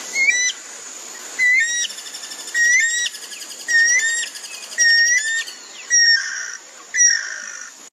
ringtone urraca